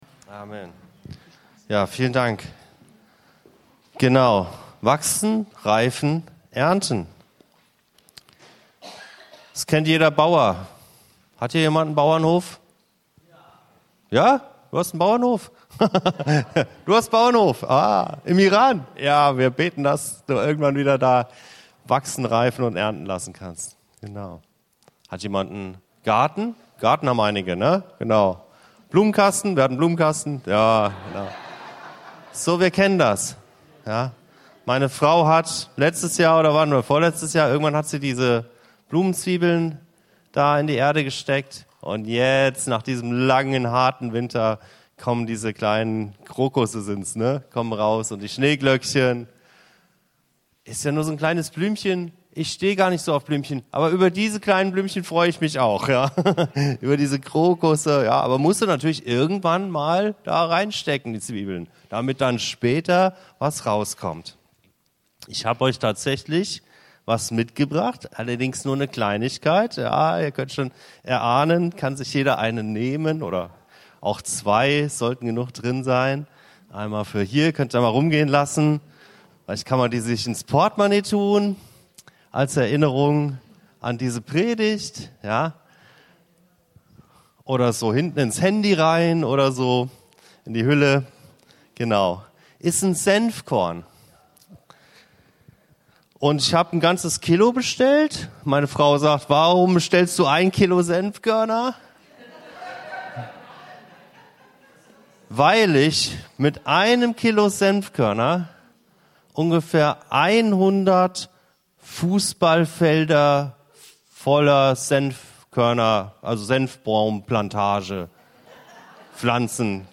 Die zweite Predigt unserer Predigtreihe: Lets grow! Du erntest was du säst- Ausgehend vom Bild des Senfkorns geht es um die Frage, wie unsere Entscheidungen, Worte und Haltungen langfristig Wirkung entfalten.